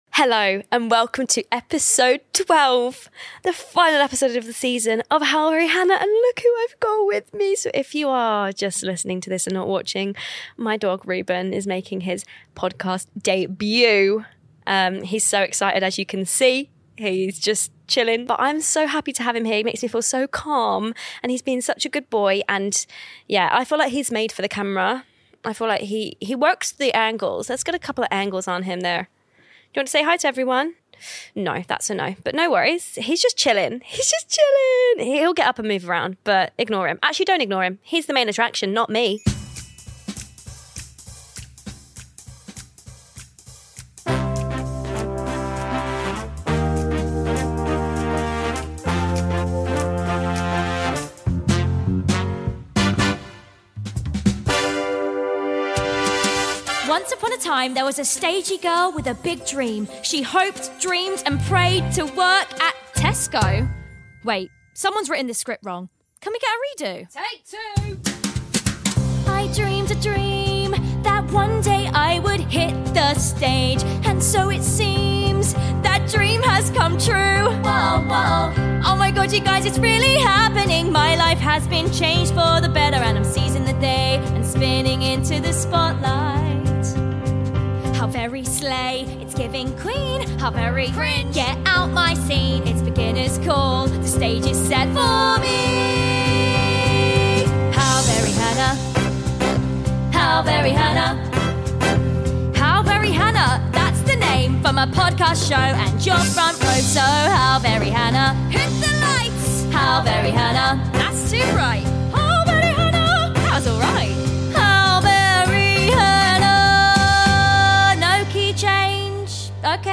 So I had to go out with a bang (and a bark 🐶).